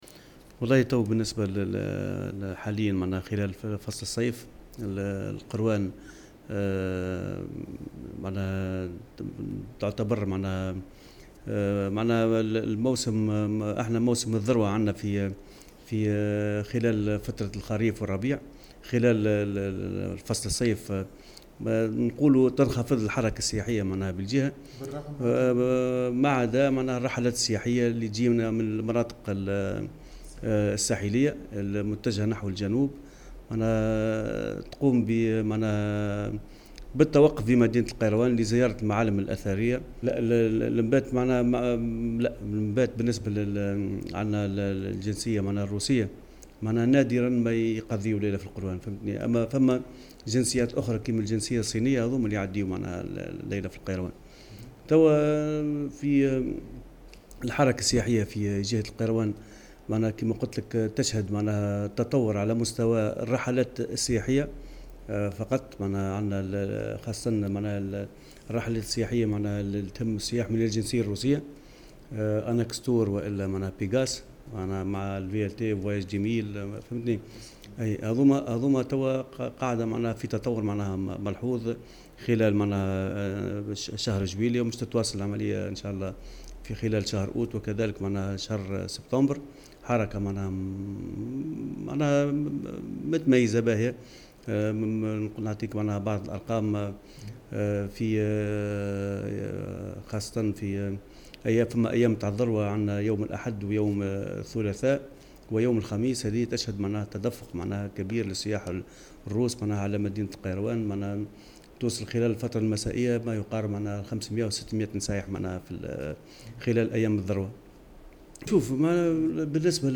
أكد المندوب الجهوي للسياحة بالقيروان خالد قلوعية في تصريح لمراسل الجوهرة "اف ام" بالجهة أن هناك تحسنا في المؤشرات السياحية بالجهة منذ شهر جانفي وإلى غاية 10 جويلية الجاري.